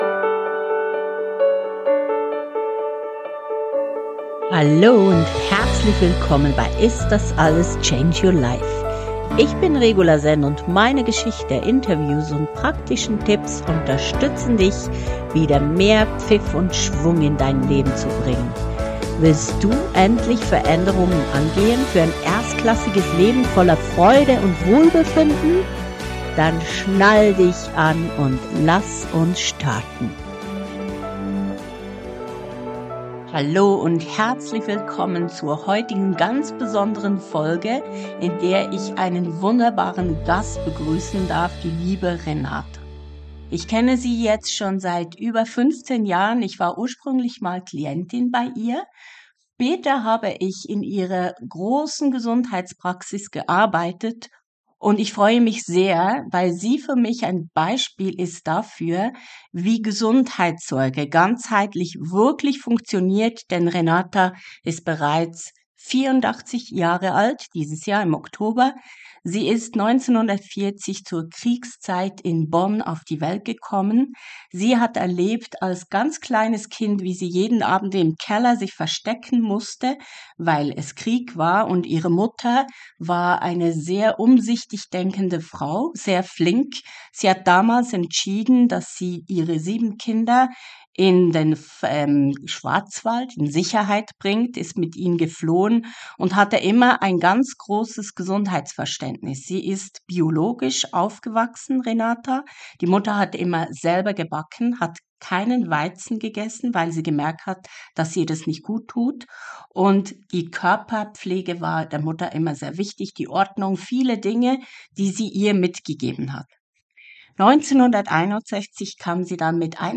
Viel Spass und Inspiration in diesem Interview!